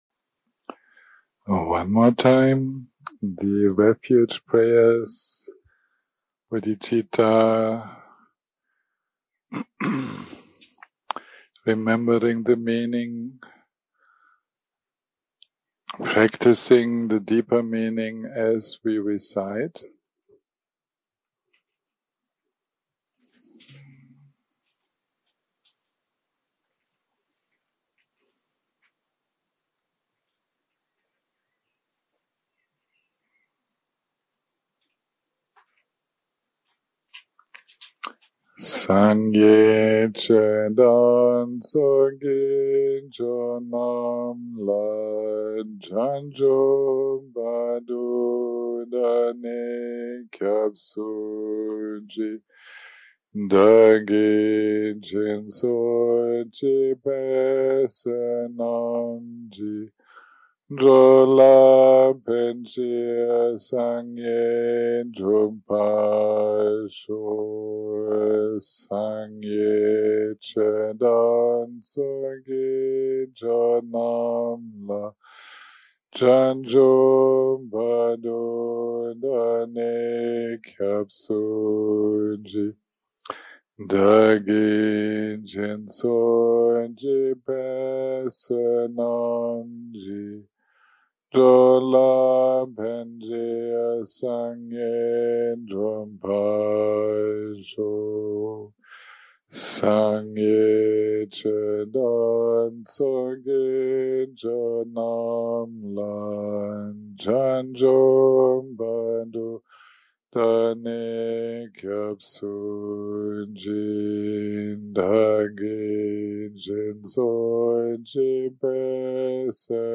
day 9 - recording 32 - Afternoon - Meditation, Discussion and Q&A